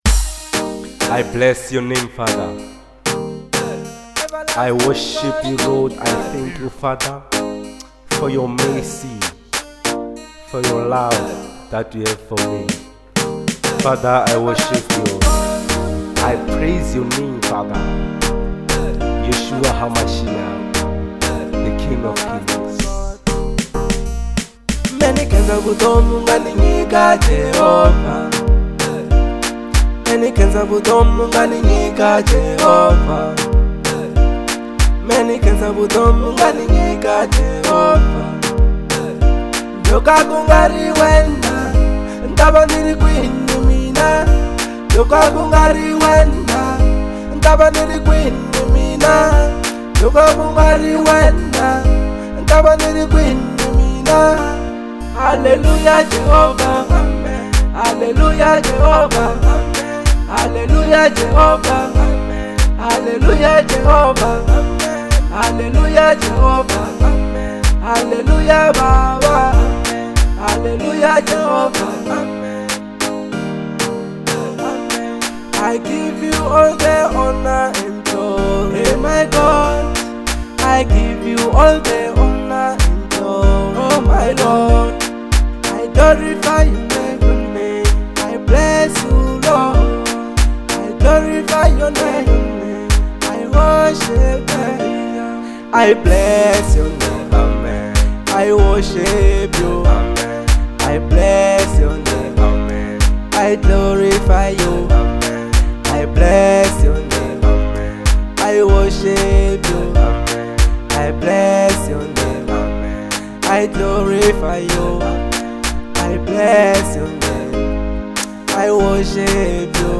03:10 Genre : Afro Pop Size